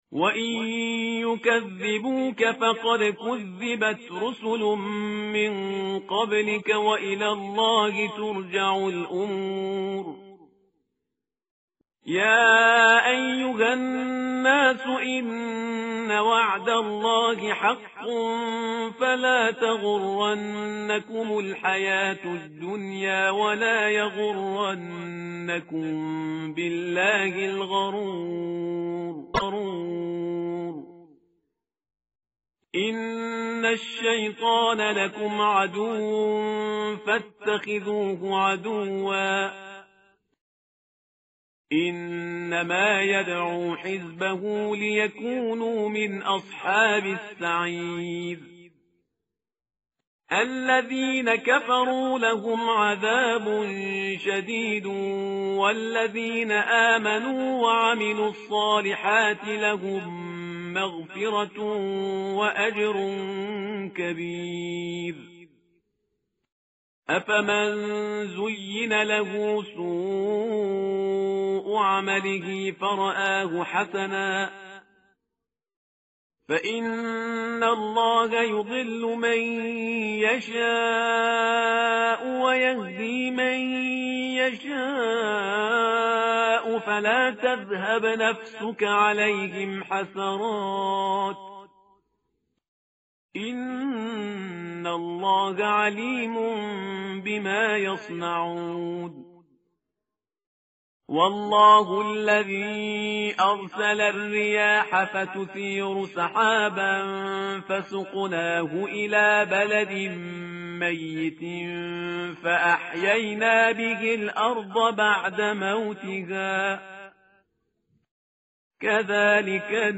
tartil_parhizgar_page_435.mp3